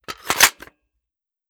fps_project_1/5.56 M4 Rifle - Magazine Loading 002.wav at 81f86396e0a792b68fc0323b85194b1a2ec71c86